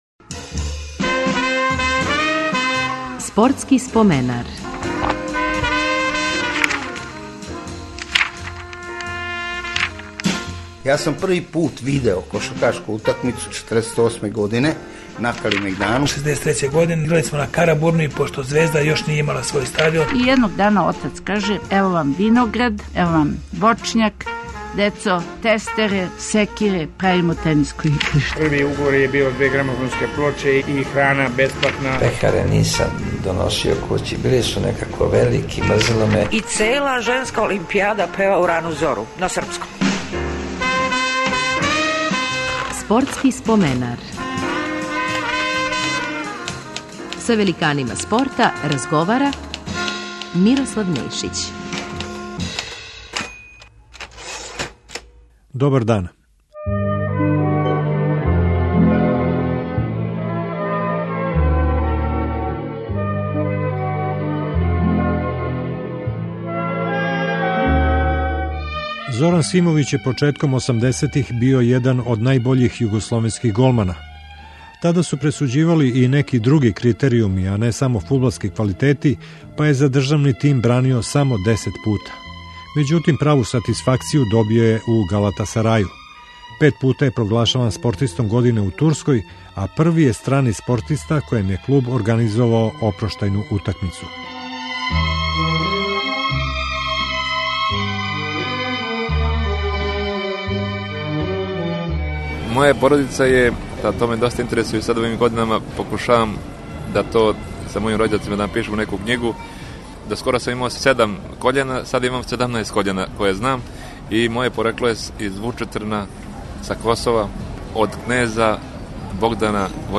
Гост ће нам бити фудбалер Зоран Симовић.
У емисији ће између осталог говорити о својим узорима, некадашњим дербијима "велике четворке", слави и популарности у Турској... Биће коришћени и архивски материјали из меча Југославија-Бугарска уз чувене коментаре Младена Делића.